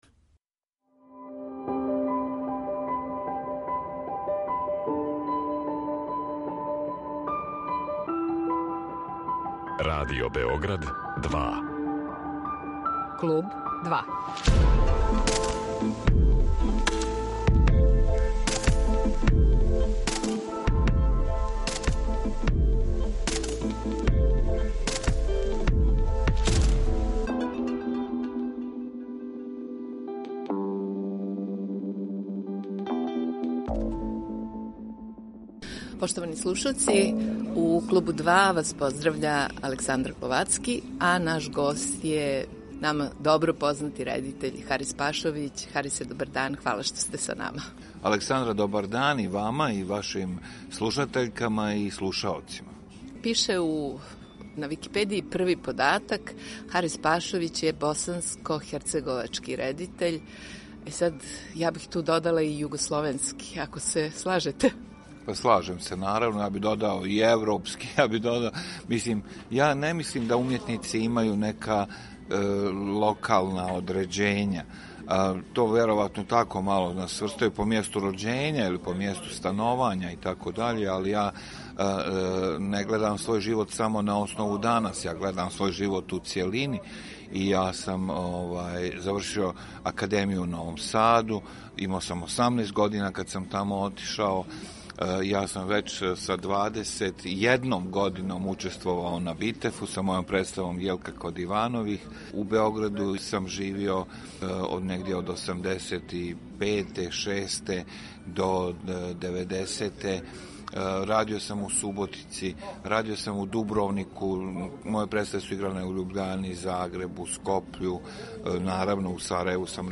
Гост је редитељ Харис Пашовић.